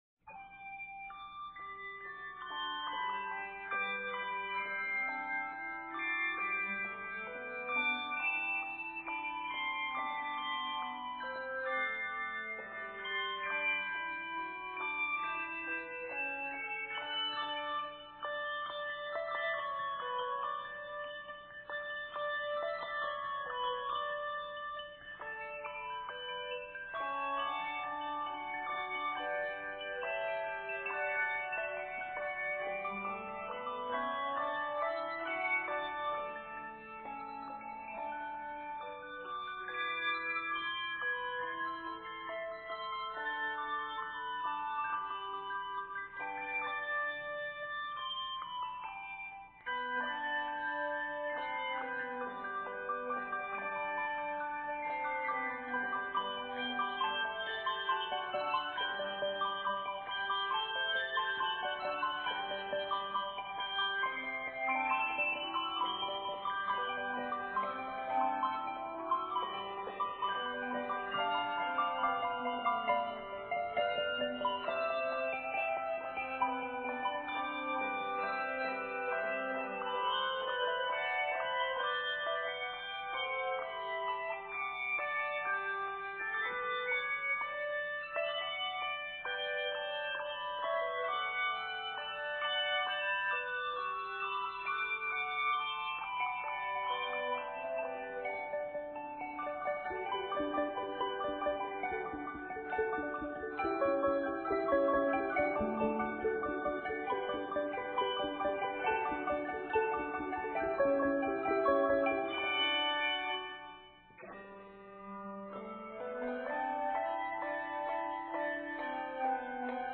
energetic 4 to 5 octave piece
Set in G Major, measures total 69.